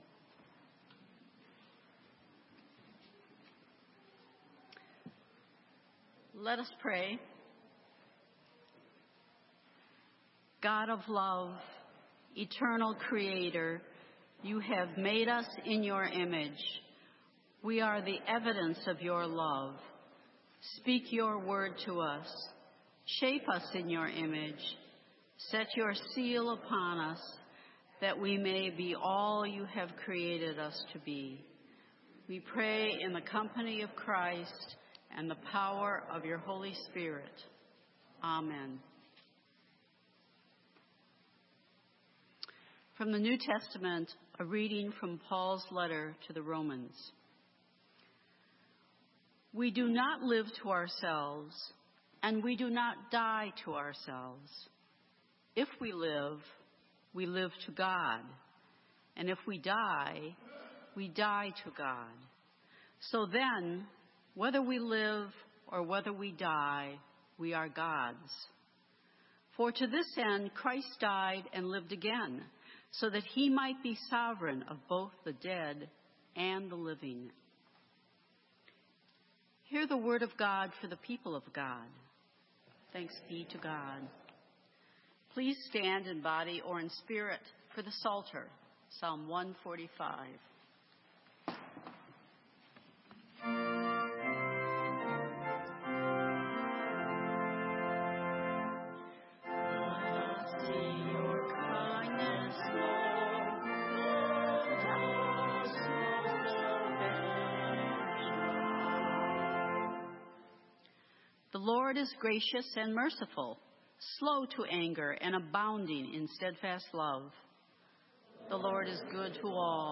Sermon:...And to God what is God's - St. Matthews United Methodist Church